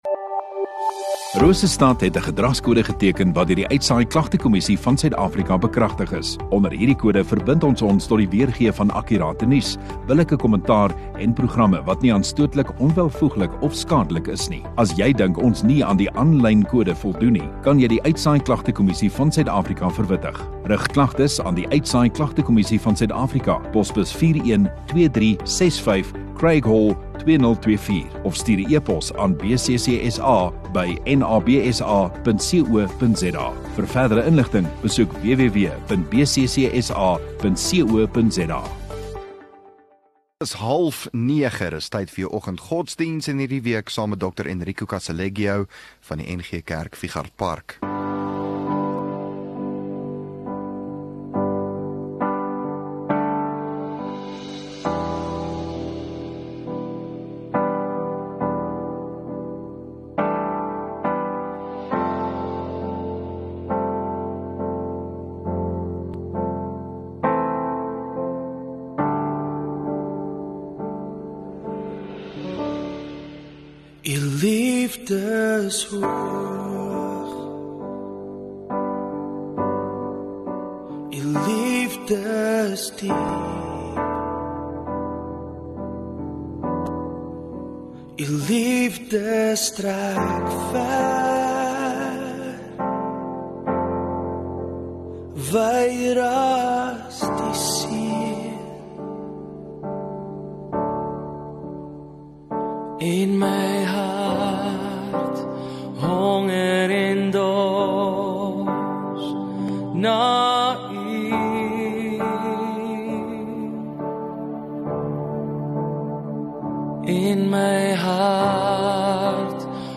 9 Jul Woensdag Oggenddiens